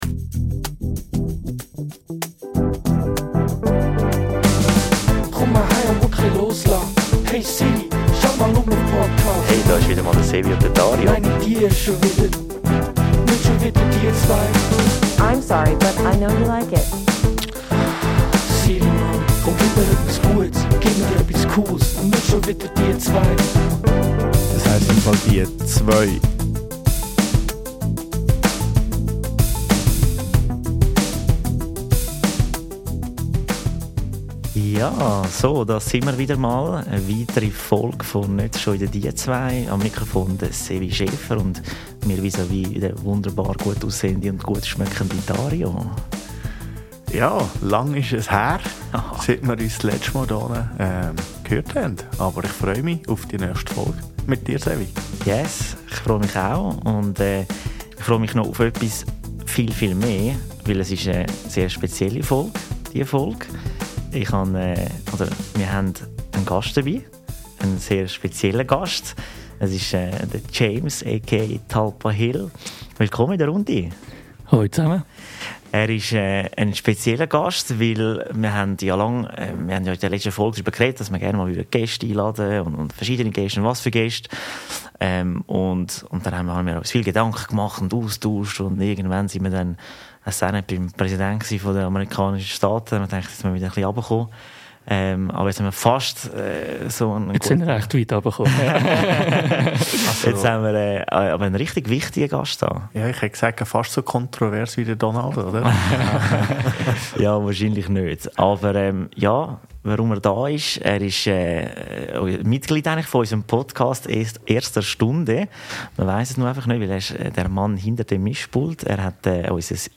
Wir sprechen das erste Mal mit einem Gast.